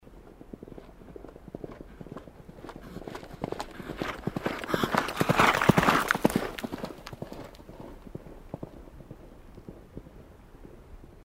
На этой странице собрана коллекция натуральных звуков лошадей.
Лошадь пронеслась мимо галопом